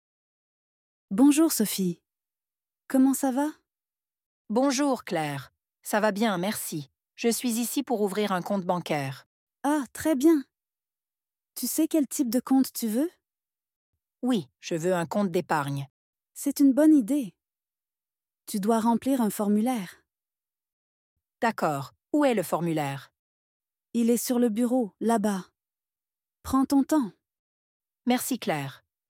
Dialogue Facile Français avec Audio à la Banque A2 2026
In this dialogue, we see a natural conversation between two friends at the bank. The informal tone is appropriate for a friendly interaction and showcases common vocabulary related to banking.